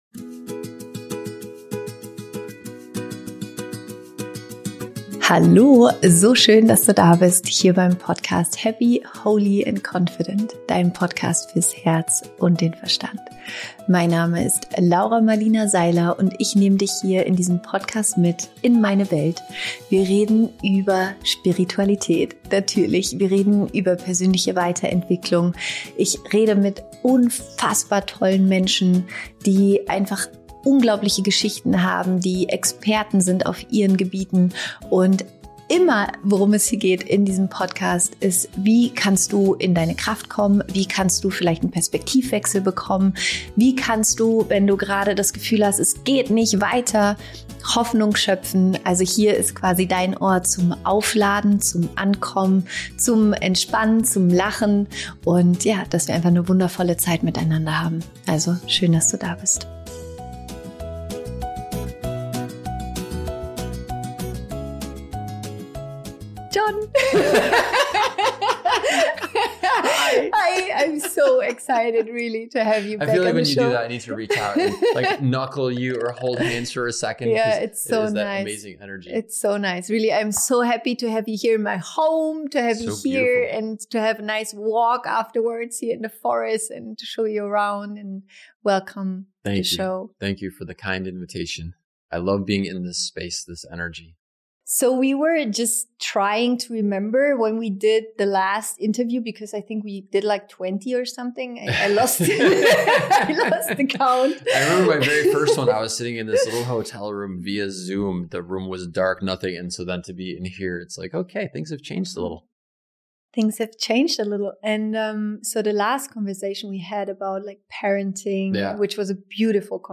Im Gespräch mit John Strelecky erfährst du, …